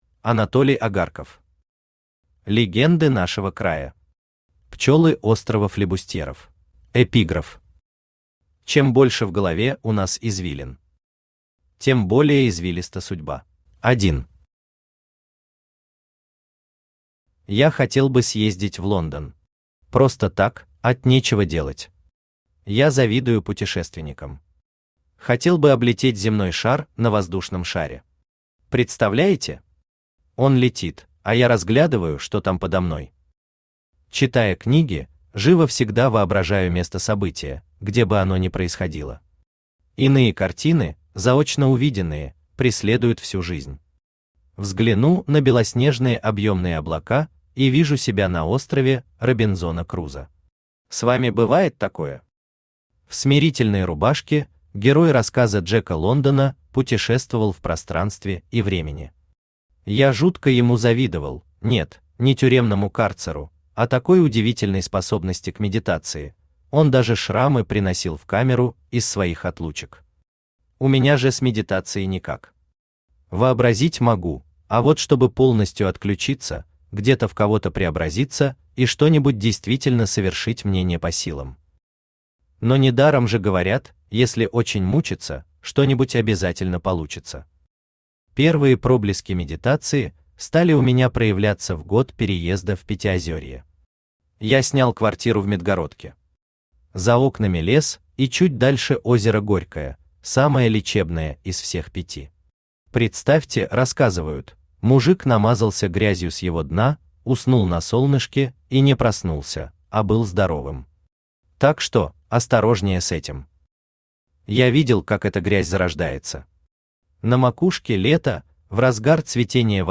Аудиокнига Легенды нашего края. Пчелы острова Флибустьеров | Библиотека аудиокниг
Пчелы острова Флибустьеров Автор Анатолий Агарков Читает аудиокнигу Авточтец ЛитРес.